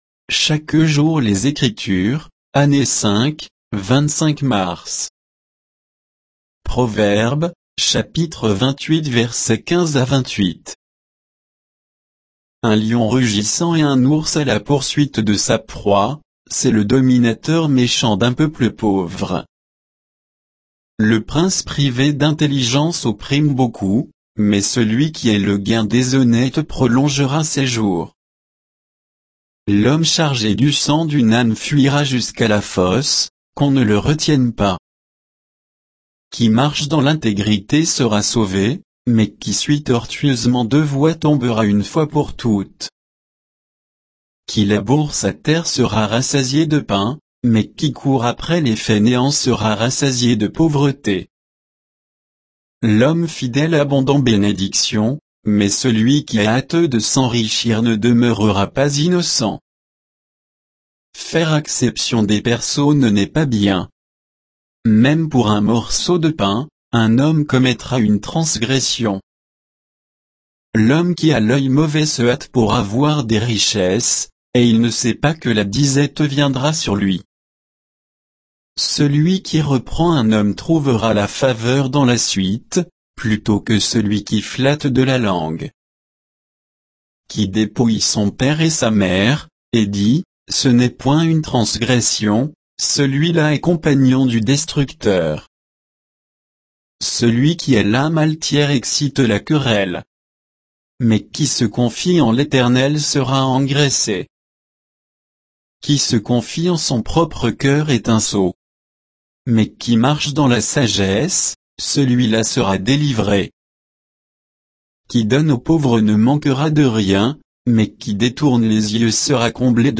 Méditation quoditienne de Chaque jour les Écritures sur Proverbes 28, 15 à 28